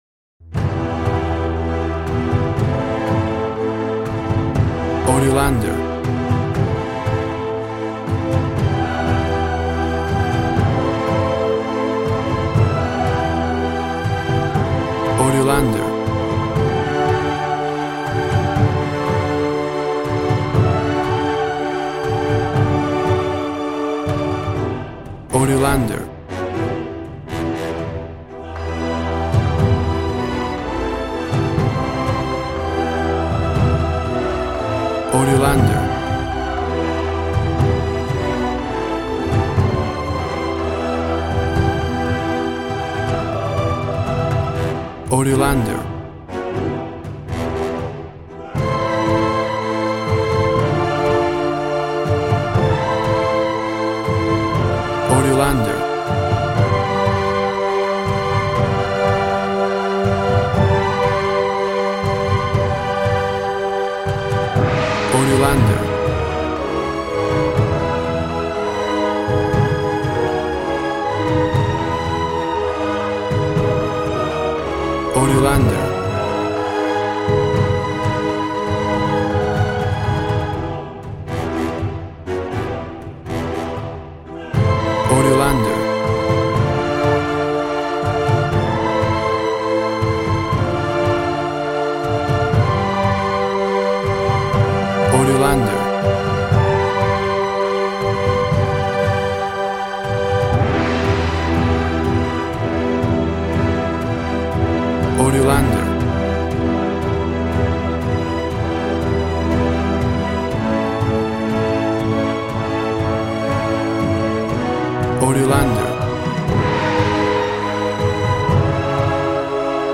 Action and Fantasy music for an epic dramatic world!
Tempo (BPM) 115